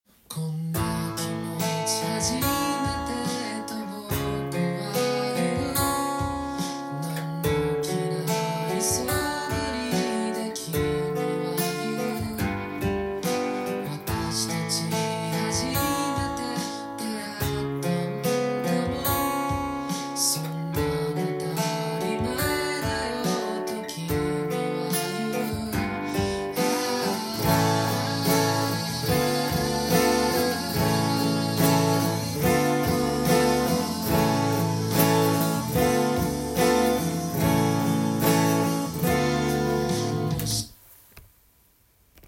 アコースティックギターでアルペジオ練習
音源に合わせて譜面通り弾いてみました
カポタストを２フレットに装着すると